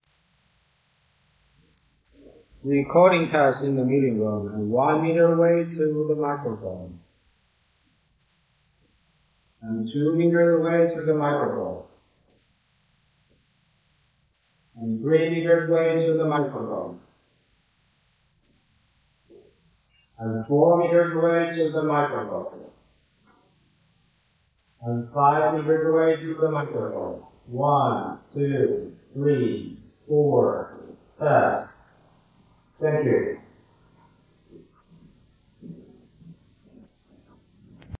Raw recording (Regular mic, no noise reduction)